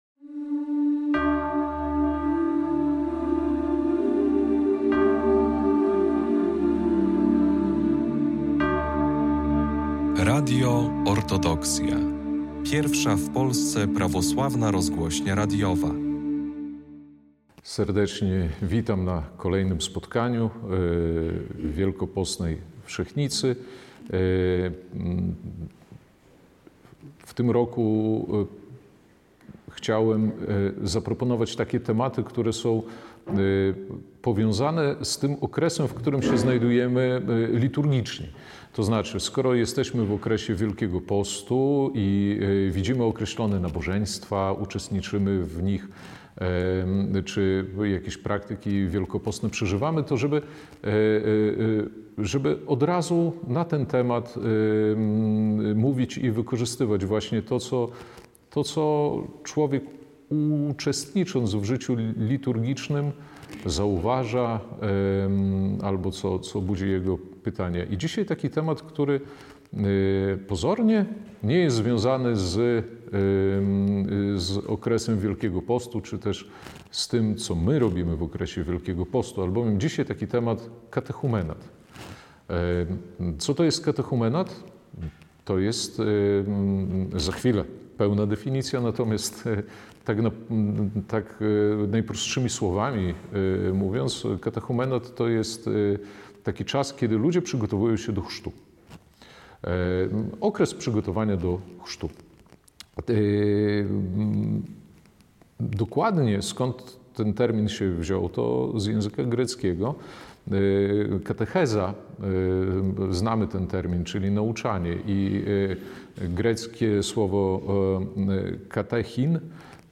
wykład